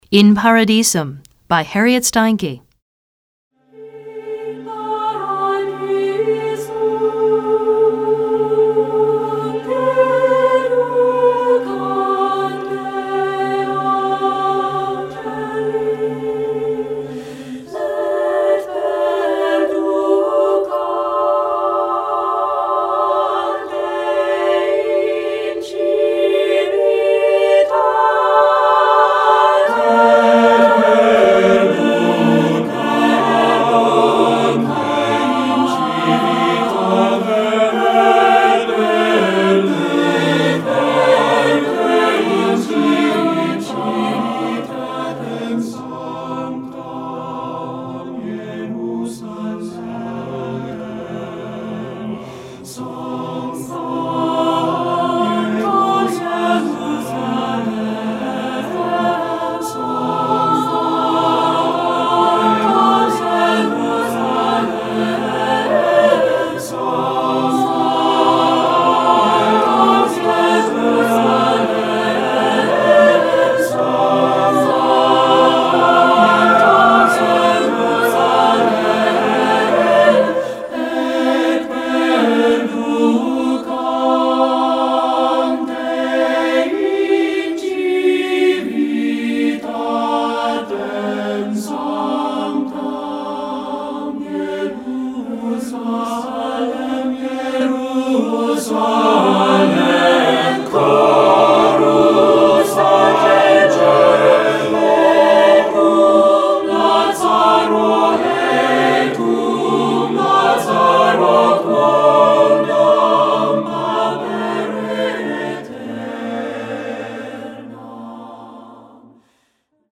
Choeur Mixte (SATB) a Cappella